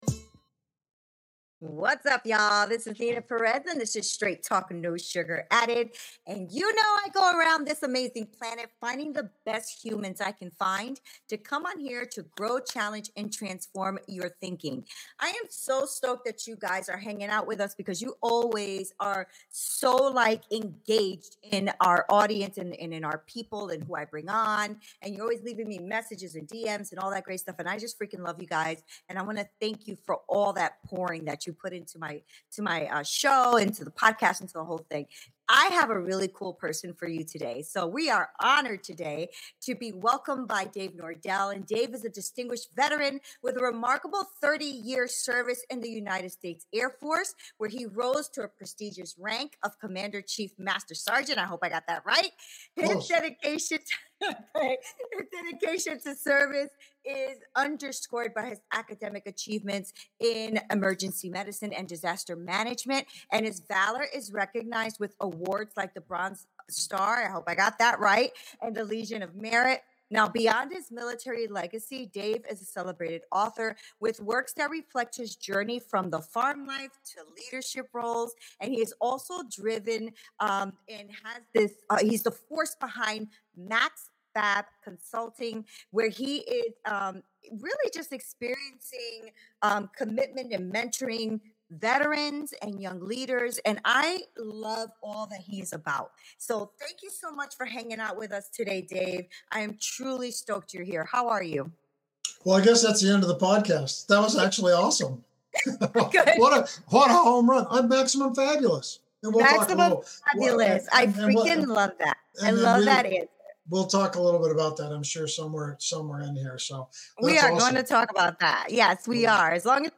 Straight Talk No Sugar Added Podcast
🎖 We're about to unpack the heavy stuff - the kind of raw, real talk you've come to expect from us. This conversation is all about personal growth, strategic guidance, and finding the resilience to navigate life's toughest challenges.